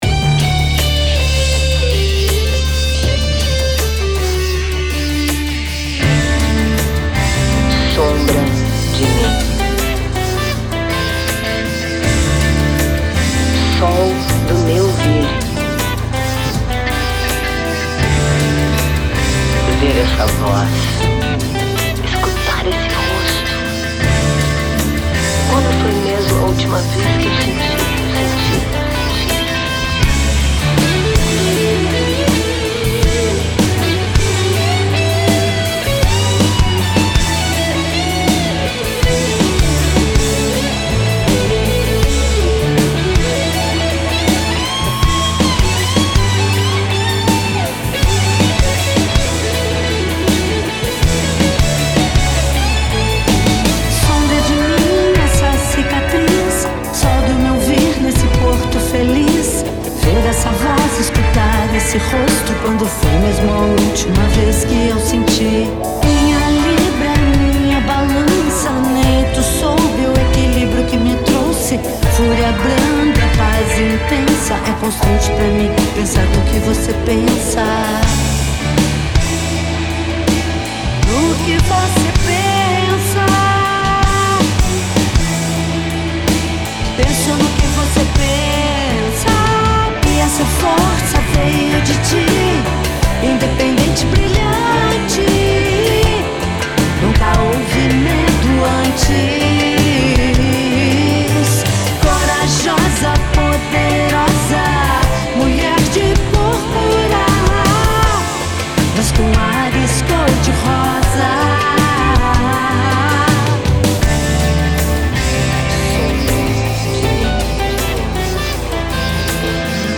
que gravou a guitarra solo e o baixo acústico.